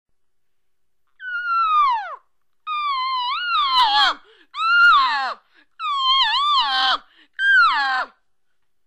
Cow Elk Estrus Mew Sounds Estrus Mew The Estrus Mew is longer than the Long Mew and a little raspy at the end. This sound is of a cow elk needing to meet with a bull elk to make babies, and time is running out!
estrus_mew2.wma